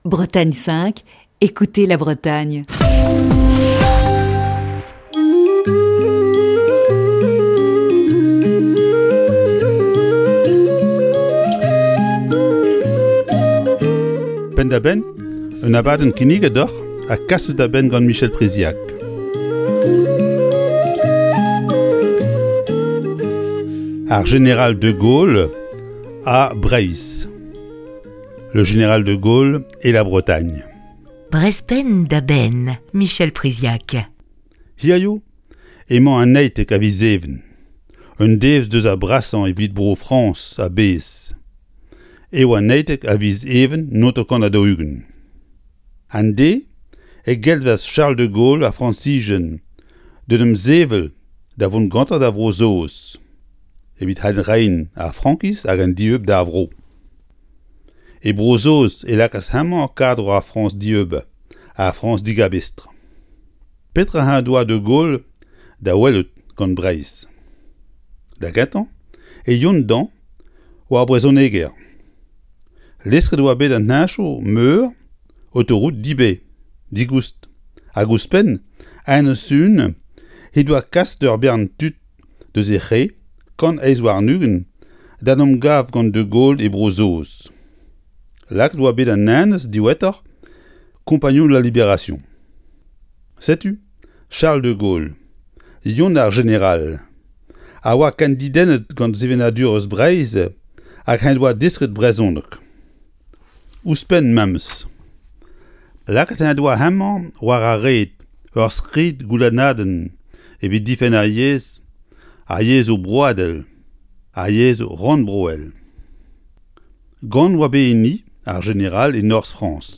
Chronique du 18 juin 2020. Journée spéciale 80 ans de l'appel du 18 juin.